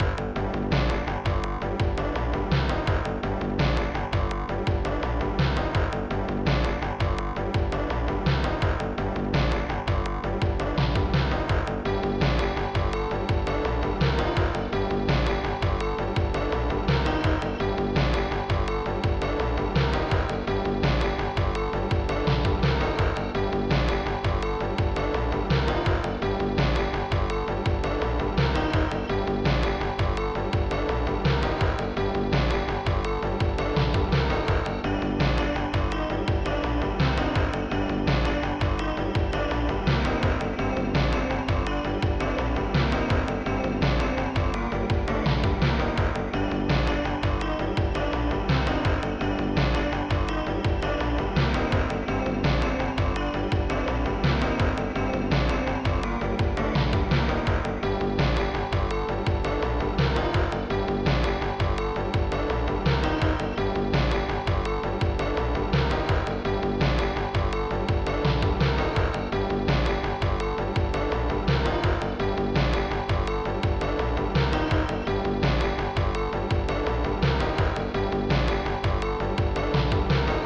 mod (ProTracker MOD (6CHN))
Fast Tracker 6CHN